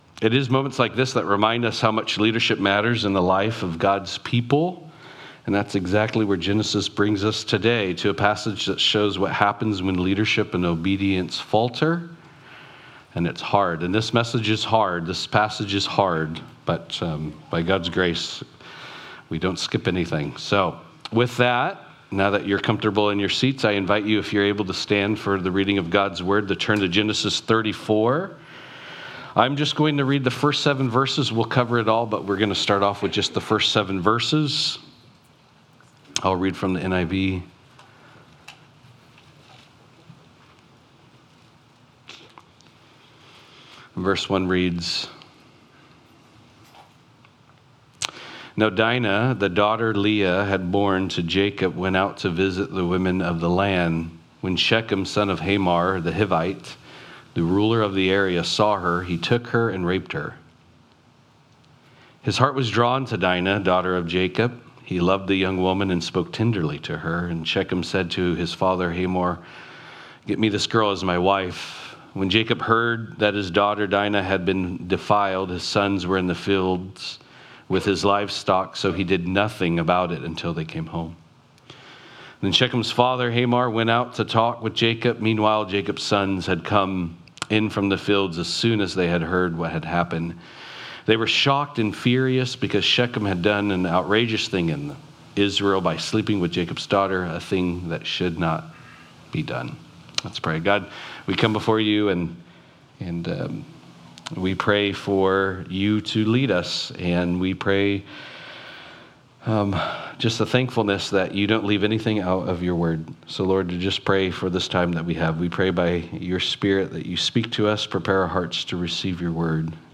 Genesis Service Type: Sunday Morning « Genesis-In the Beginning